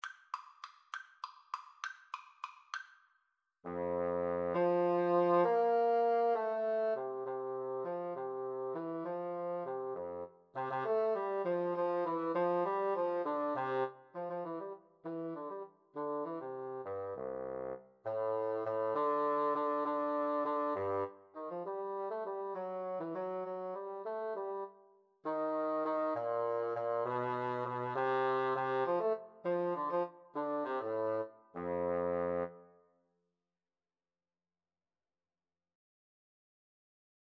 3/8 (View more 3/8 Music)